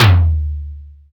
Index of /90_sSampleCDs/Roland L-CD701/DRM_Analog Drums/TOM_Analog Toms
TOM DDR TOM4.wav